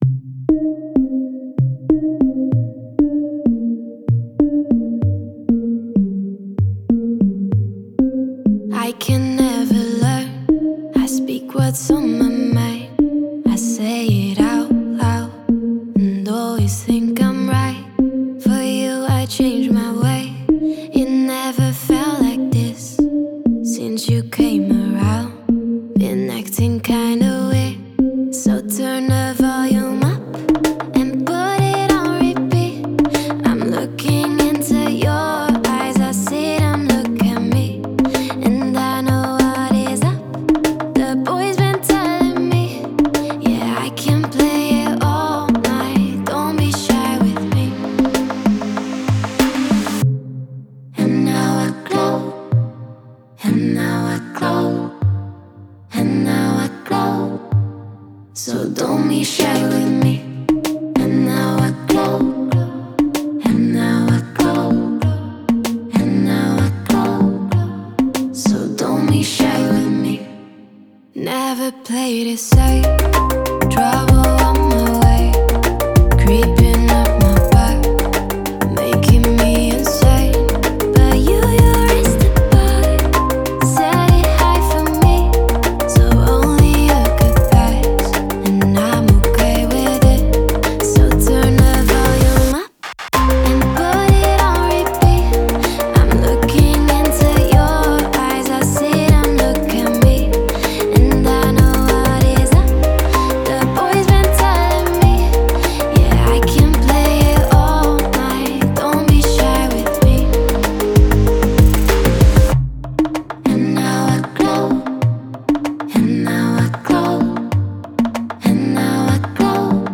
нежная и атмосферная композиция
инди-поп